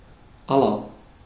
Ääntäminen
Ääntäminen Tuntematon aksentti: IPA: /ˈa.lɐ/ Haettu sana löytyi näillä lähdekielillä: saksa Käännöksiä ei löytynyt valitulle kohdekielelle.